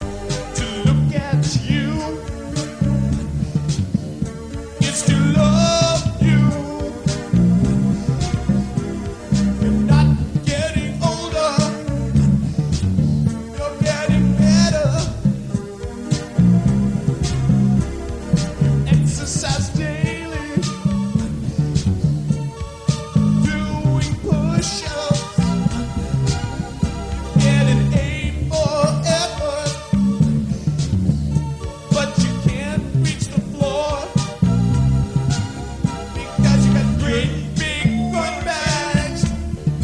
keyboards and beatbox